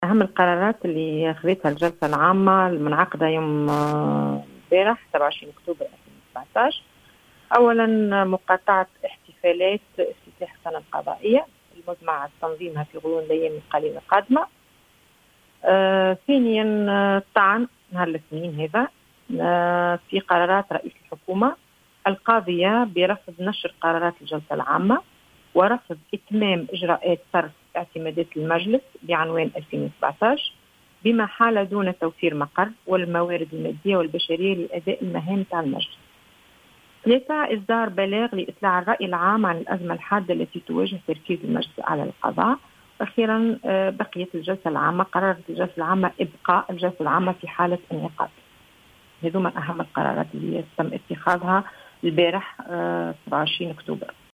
قالت عضو لجنة الإعلام بالمجلس الأعلى للقضاء، بسمة السلامي إنه تم الاتفاق خلال الجلسة العامة المنعقدة يوم أمس، على الطعن يوم الاثنين القادم في قرارات رئيس الحكومة القاضية برفض نشر قرارات الجلسة العامة و عدم إتمام إجراءات صرف الاعتمادات المخصصة للمجلس. وأضافت السلامي اليوم في تصريح لـ"الجوهرة أف أم" أنه ستتم أيضا مقاطعة احتفالات السنة القضائية وإصدار بلاغ لإطلاع الرأي العام على الأزمة الحادّة التي تواجه تركيز المجلس الأعلى للقضاء.